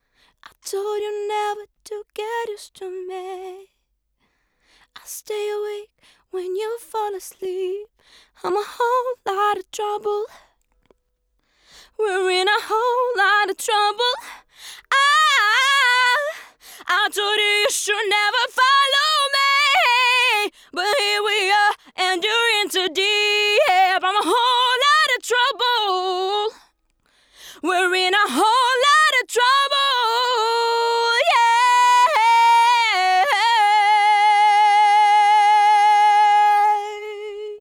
Нажмите для раскрытия... 4:1, атака и релиз самые быстрые, gr до 15-20.
Вложения 1176hw-Голос.wav 1176hw-Голос.wav 3,2 MB · Просмотры: 137